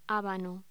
Locución: Habano